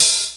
cch_06_hat_open_high_ring_thin.wav